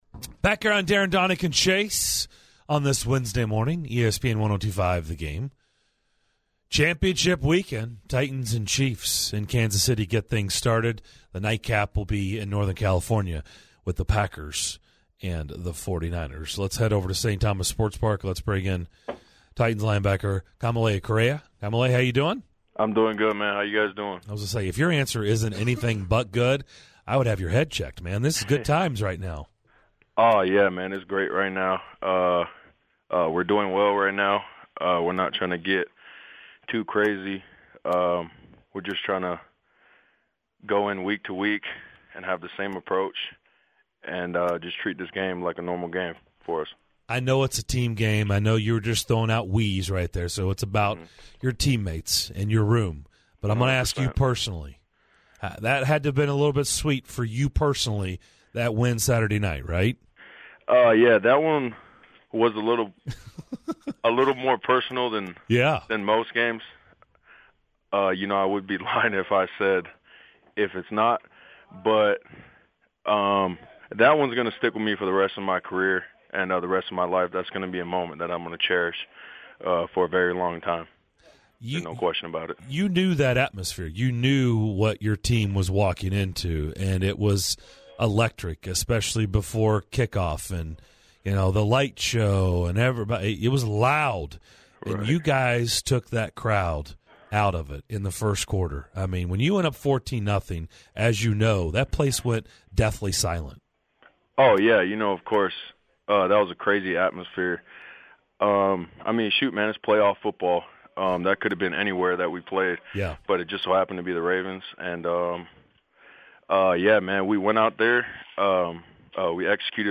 Titans linebacker Kamalei Correa joins DDC to discuss the Titans big game against the Chiefs as well as the win against his former team: the Baltimore Ravens.